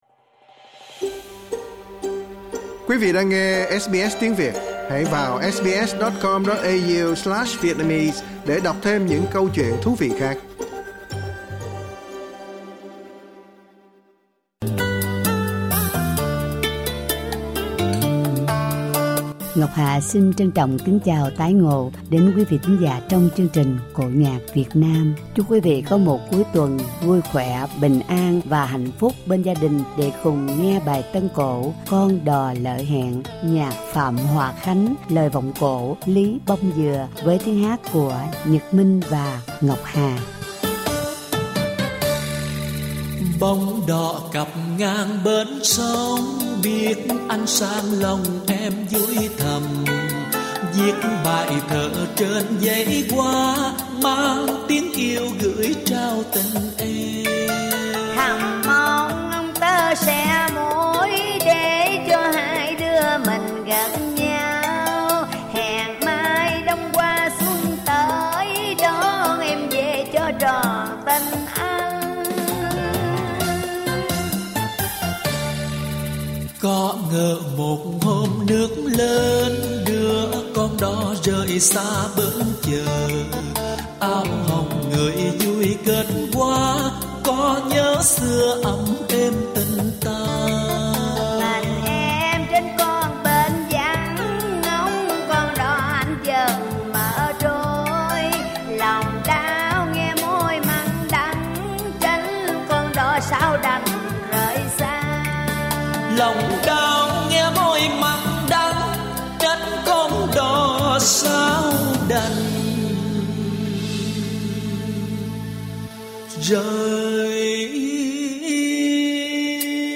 Tân cổ